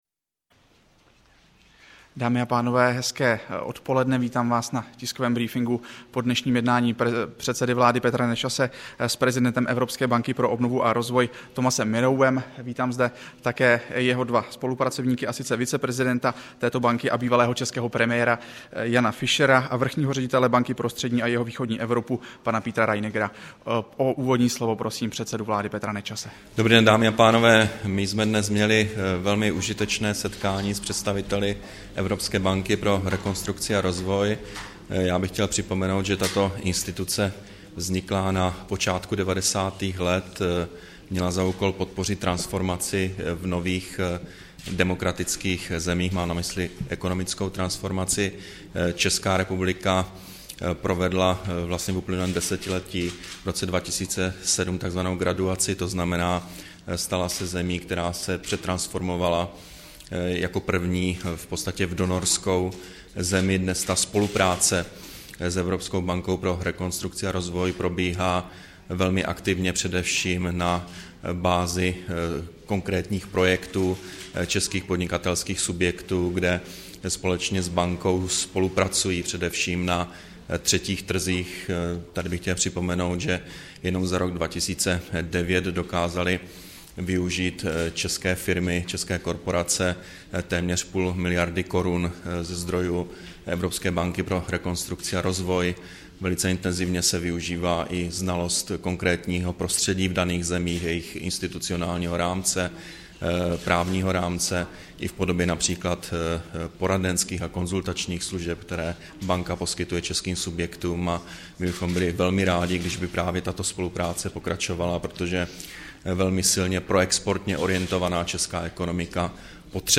Tískový brífink předsedy vlády Petra Nečase po jednání s prezidentem Evropské banky pro obnovu a rozvoj Thomasem Mirowem, 7. února 2011